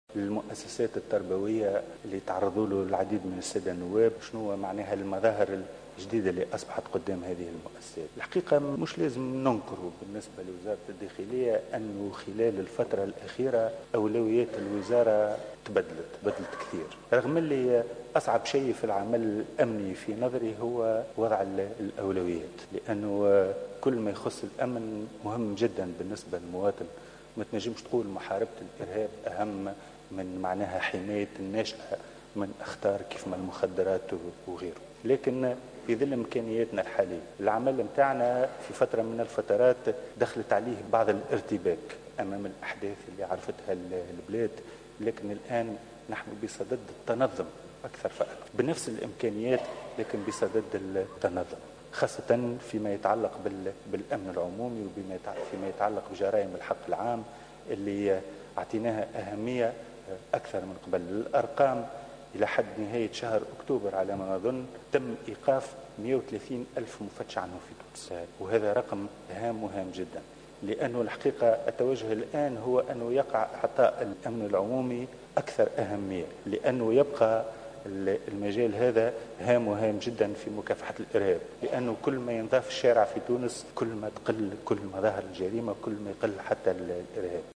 وأبرز مجدوب، ، لدى حضوره اليوم الثلاثاء للجلسة العامة بمجلس نواب الشعب المخصصة لمناقشة ميزانية الدولة لسنة 2017 والمصادقة على ميزانية وزارة الداخلية، أن قوات الأمن الوطني مازالت بصدد "التنظم" بعد الارتباك الحاصل جراء الأحداث التي شهدتها البلاد، وهو ما يتيح لها التعامل مع ملفات الأمن العام وحماية الناشئة في المؤسسات التربوية بالتوازي مع تواصل مجهودات مكافحة الإرهاب.